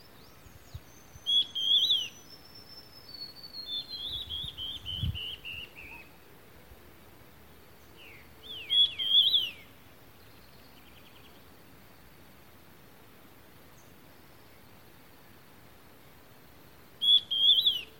Batará Estriado (Myrmorchilus strigilatus)
Nombre en inglés: Stripe-backed Antbird
Localidad o área protegida: Pampa del Indio
Condición: Silvestre
Certeza: Observada, Vocalización Grabada
batara-estriado.mp3